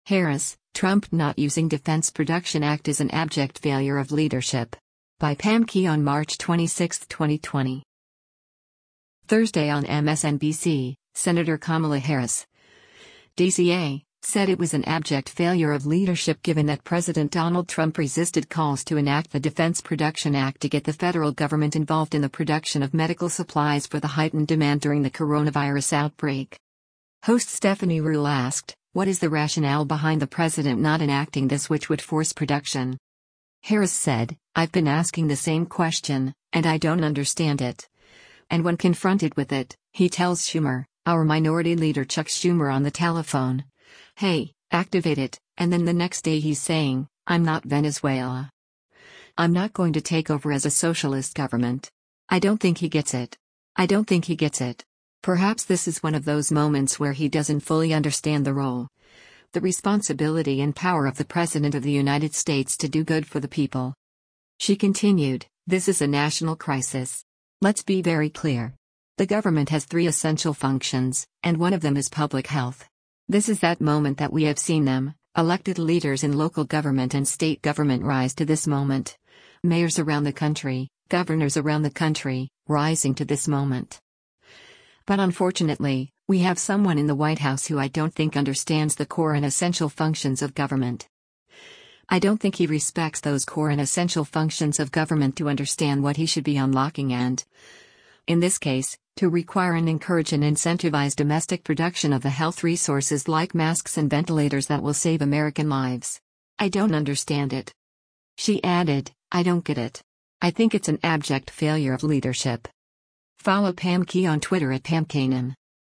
Host Stephanie Ruhle asked, “What is the rationale behind the president not enacting this which would force production?”